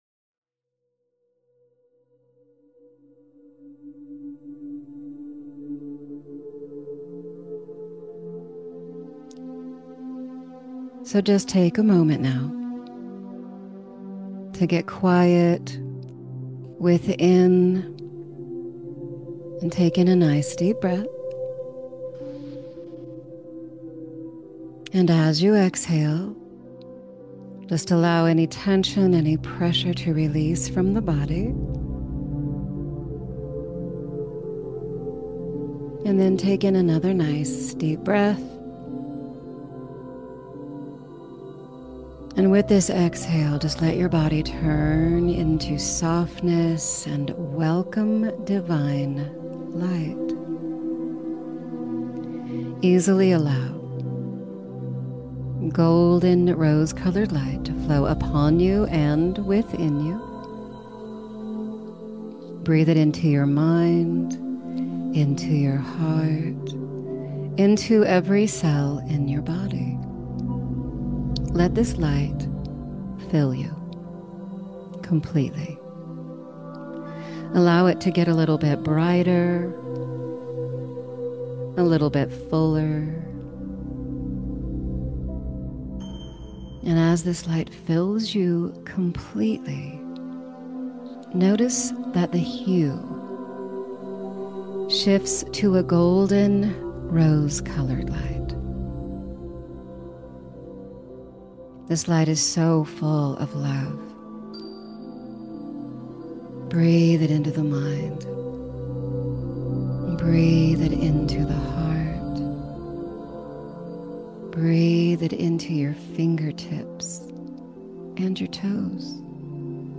Self Hypnosis Session